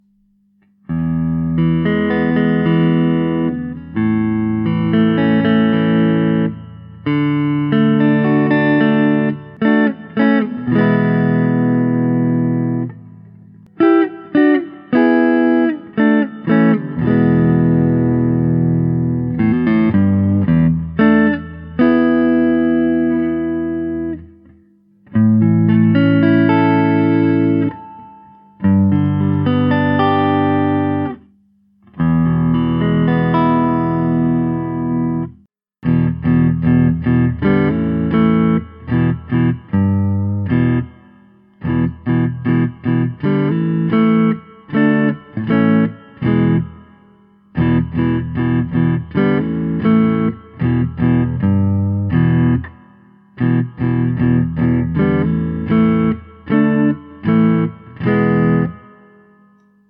The mark II Cherubim improves on the original Cherubim with tapped tones that are a little fatter and bolder while the full tones are clearer and brighter.
A P-90, with a 50's / 60's style jangly sound and a full P90 sound that rocks hard.
The standard sound samples are of Mark II Cherubims.
Neck Full      Neck Tapped